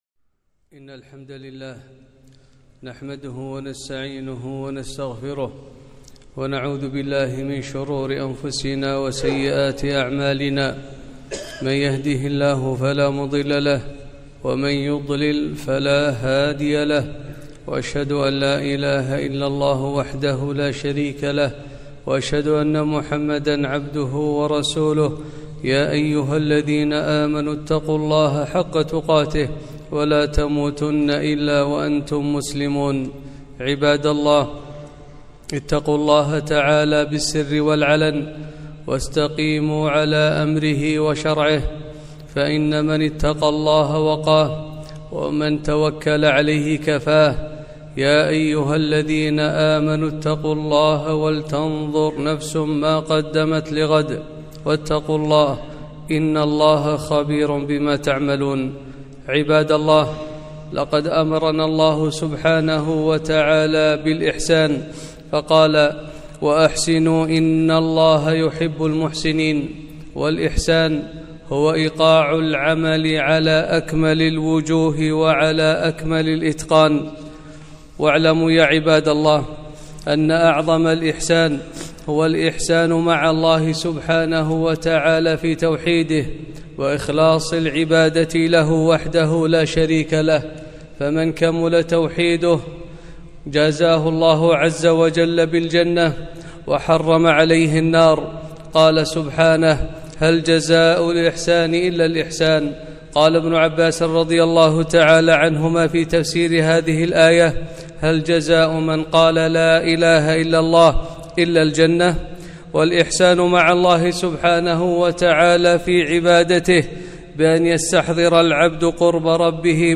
خطبة - الاحـســان